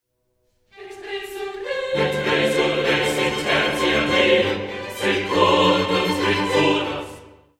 “K49” is scored for violins, violas, basses, organ continuo, and three trombones doubling alto, tenor, and bass vocal parts.
The tempo picks up briskly at “
Et resurrexit” and at the words “Et ascendit in caelum”, the music also ascends.